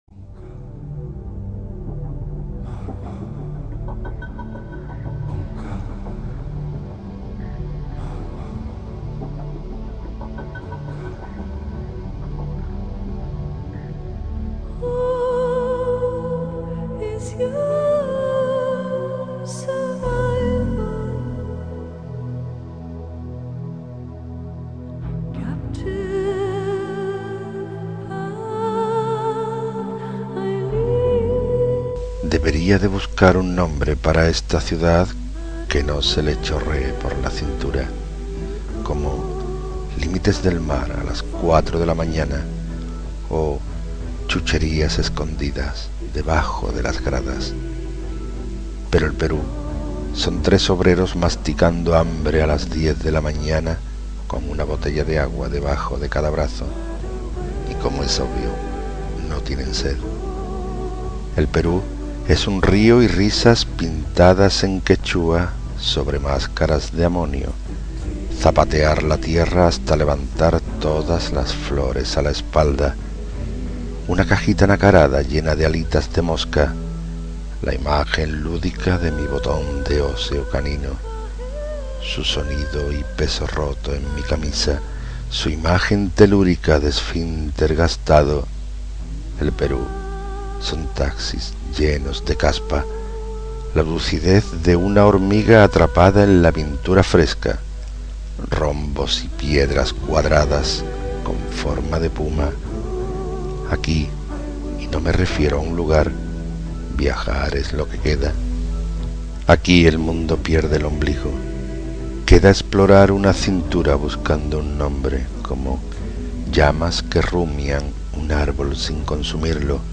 Inicio Multimedia Audiopoemas Poema de casi amor en una ciudad del Perú.
IV Certamen Poemas sin Rostro 2008-2009